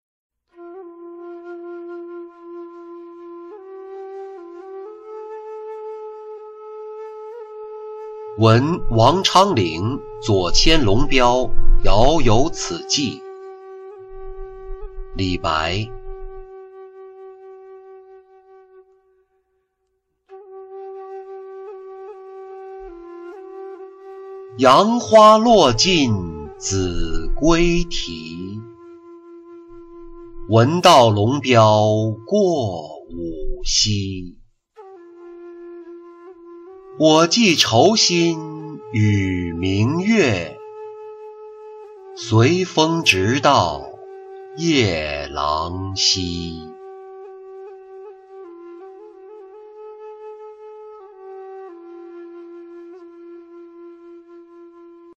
闻官军收河南河北-音频朗读